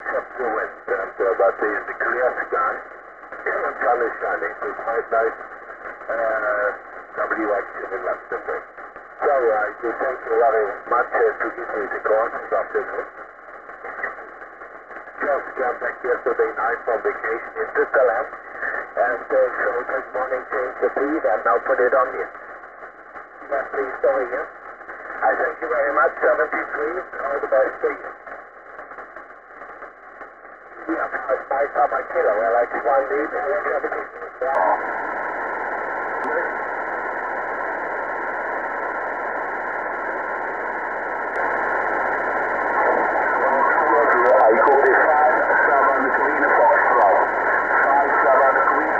Zaraz postaram się przesłać kilka nagrań sygnałów SSB.